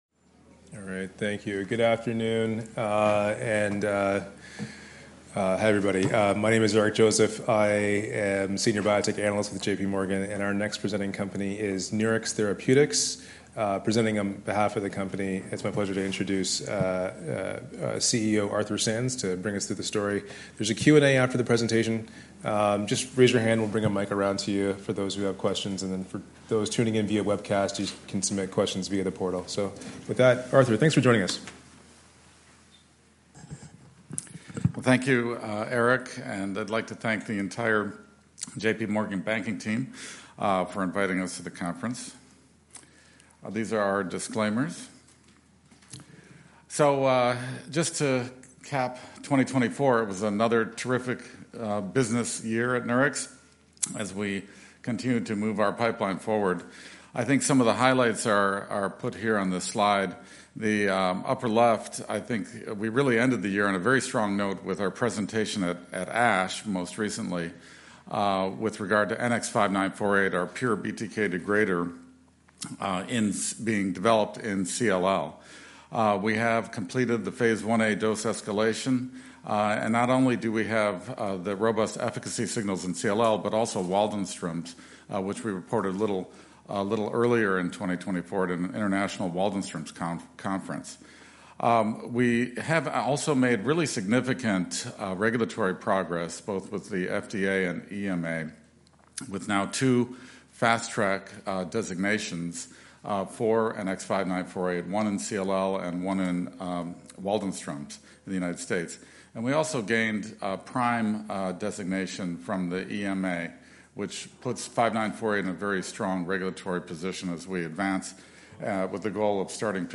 43rd Annual J.P. Morgan Healthcare Conference | Nurix Therapeutics, Inc.